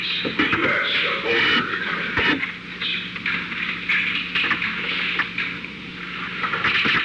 Secret White House Tapes
Conversation No. 638-10
Location: Oval Office